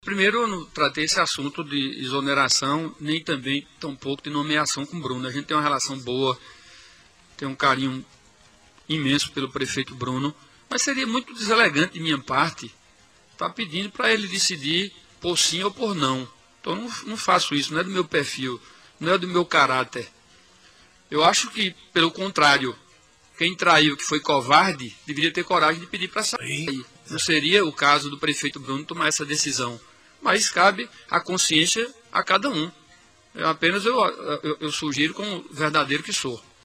Na tarde desta terça-feira (03), durante entrevista, o ex-prefeito de Campina Grande, Romero Rodrigues, foi indagado se houve uma ‘pressão’ do mesmo ao atual prefeito de Campina, Bruno Cunha Lima, para demitir os aliados do grupo Ribeiro, após a articulação de Aguinaldo para sua irmã, Daniella Ribeiro, assumir o comando do PSD na Paraíba.